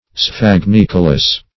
Search Result for " sphagnicolous" : The Collaborative International Dictionary of English v.0.48: Sphagnicolous \Sphag*nic"o*lous\, a. [Sphagnum + L. colere to inhabit.]